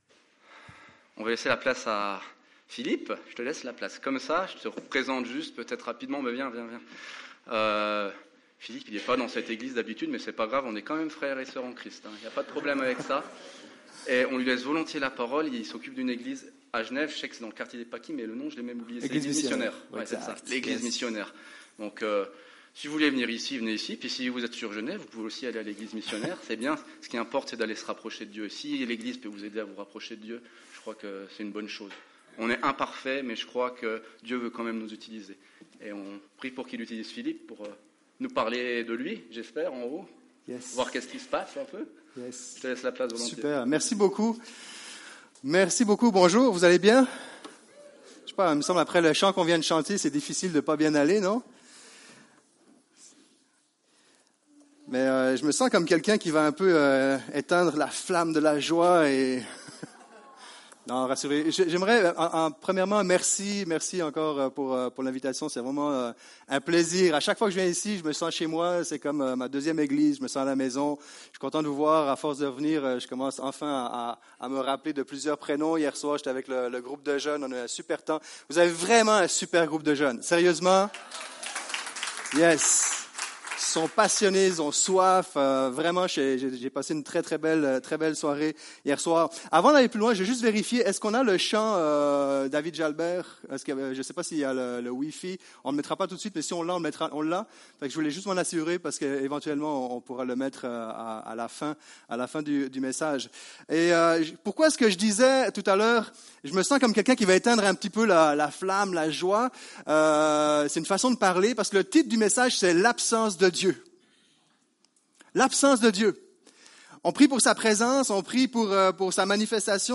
Culte du 11 mars